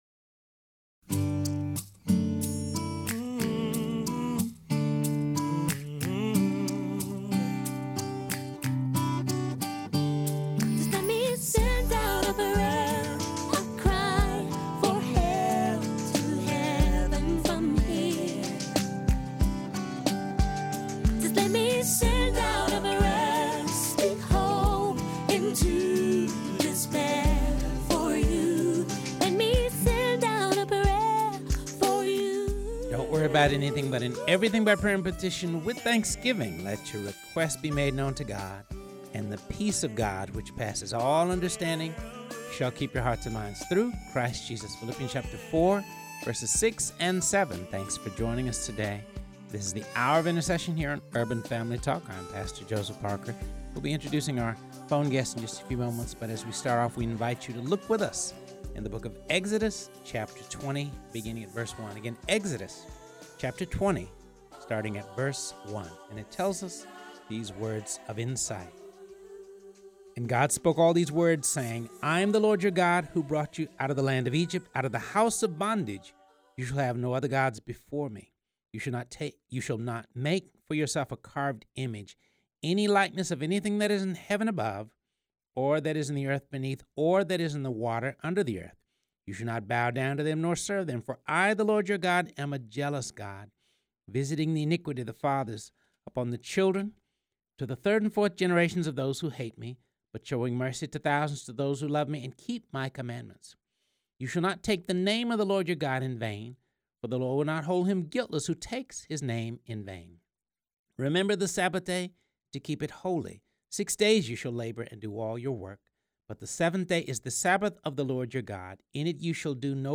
joined via phone